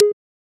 check-off.wav